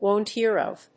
アクセントwòn't [wòuldn't] héar of…